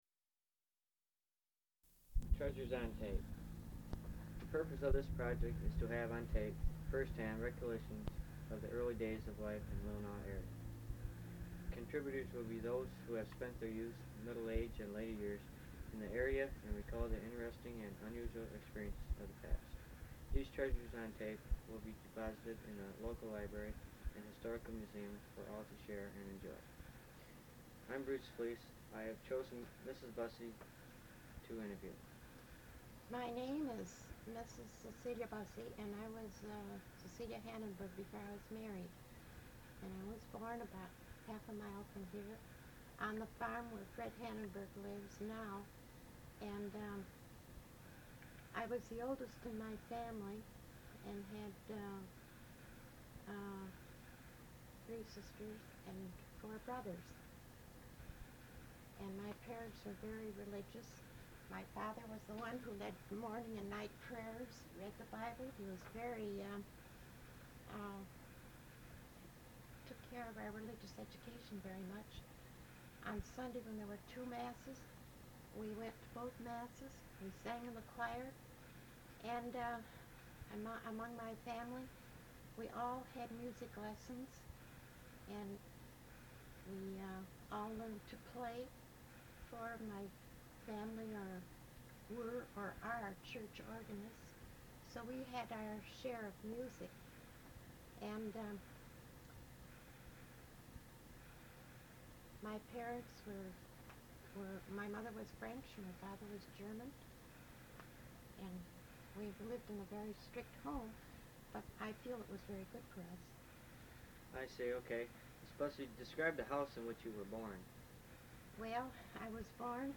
Tape. Audio Cassette.